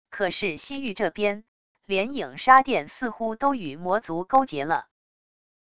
可是西域这边……连影杀殿似乎都与魔族勾结了wav音频生成系统WAV Audio Player